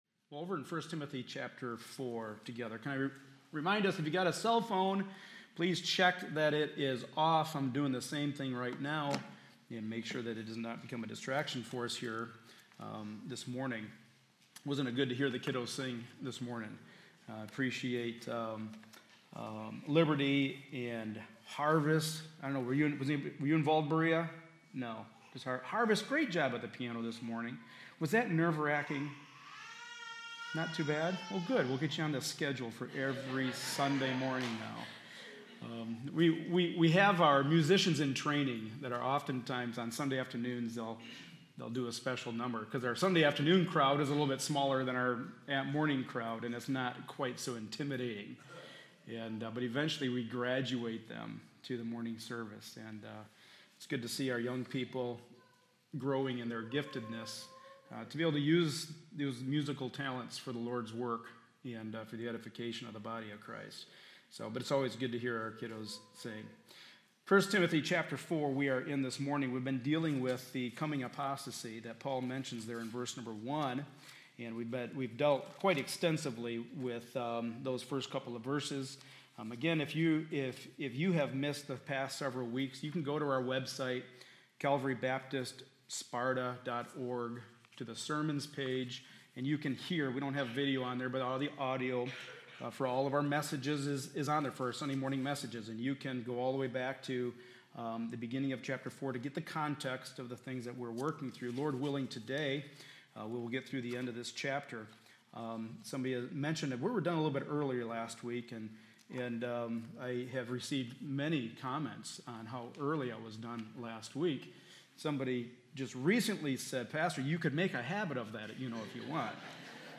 1 Timothy 4 Service Type: Sunday Morning Service A study in the Pastoral Epistles.